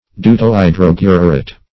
Search Result for " deutohydroguret" : The Collaborative International Dictionary of English v.0.48: Deutohydroguret \Deu`to*hy*drog"u*ret\, n. [Pref. deut-, deuto- + hydroguret.]